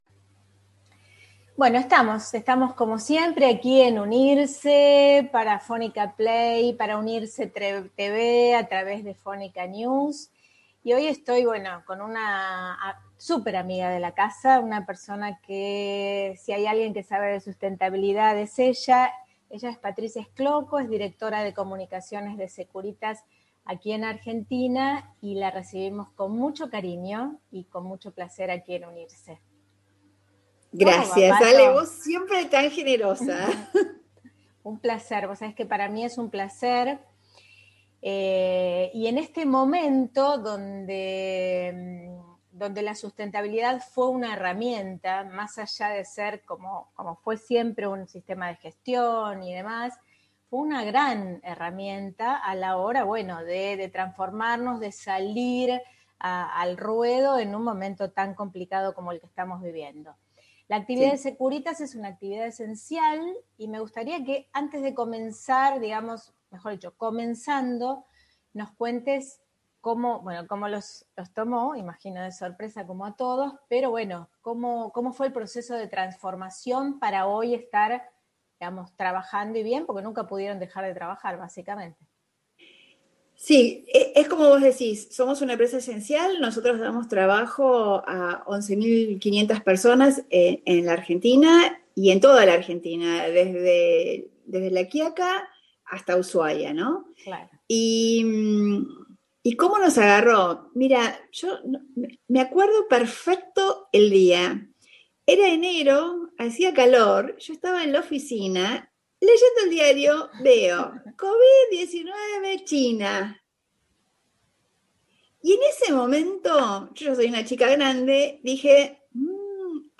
VER VIDEO DE LA ENTREVISTA